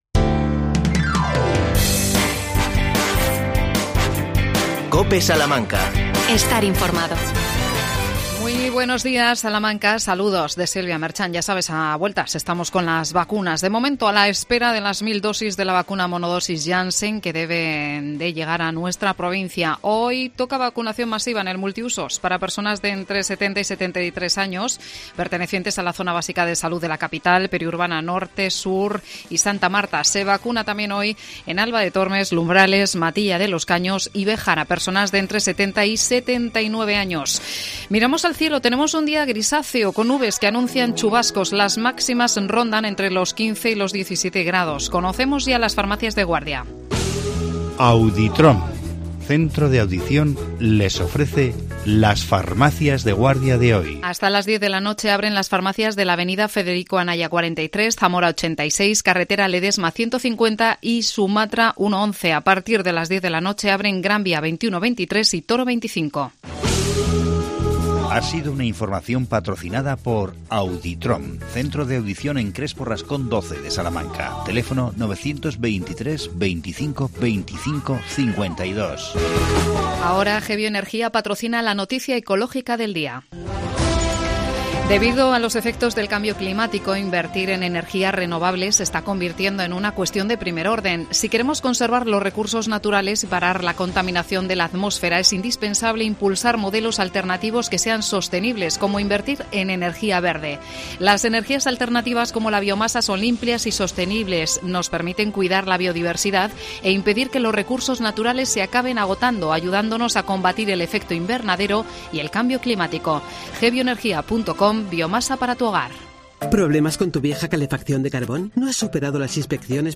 AUDIO: Entrevista a la concejala de Medio Ambiente del Ayuntamiento de Salamanca Miriam Rodríguez.El tema: nuevo parque público.